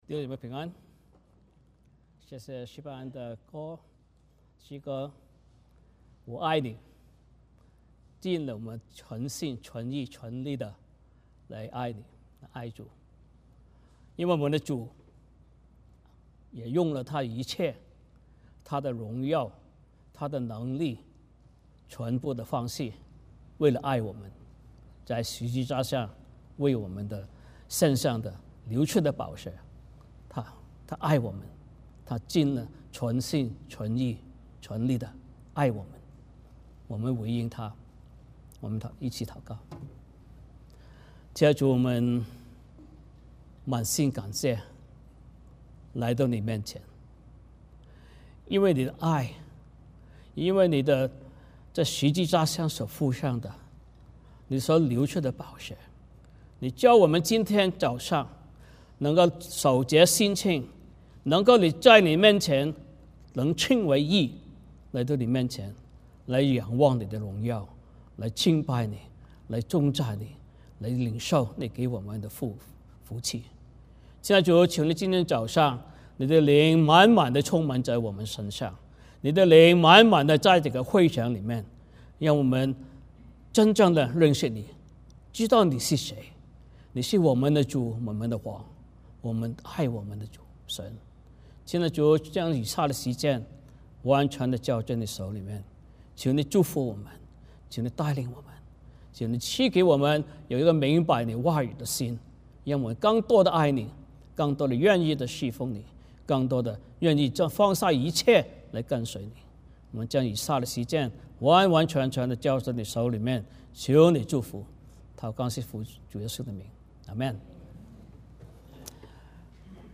使徒行传 9:1-22 Service Type: 主日崇拜 欢迎大家加入我们的敬拜。